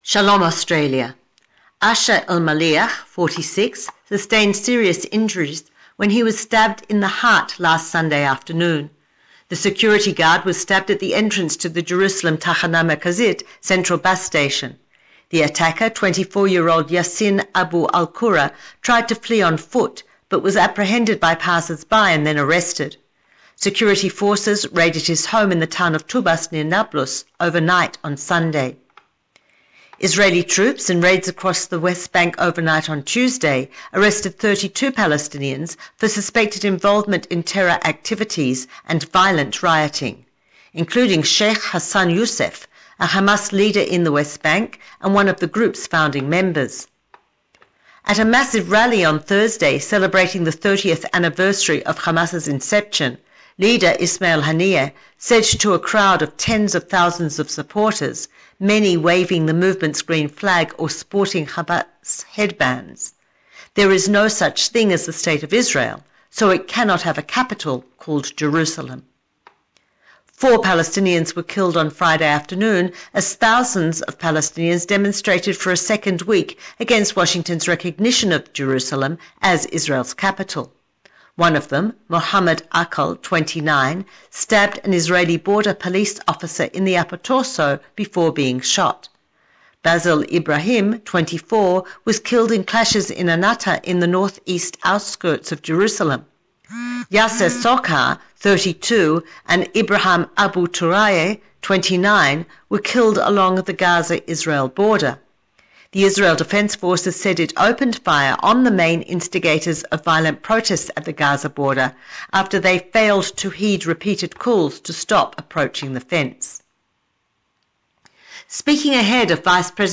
weekly current affairs report from Jerusalem
weekly current affairs report in English